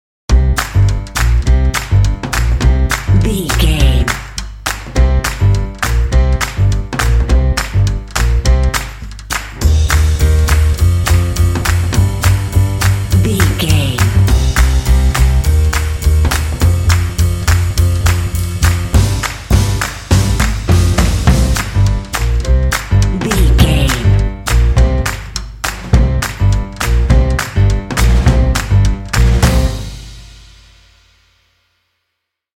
Aeolian/Minor
confident
lively
drums
bass guitar
jazz